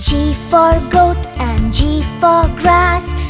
Phonics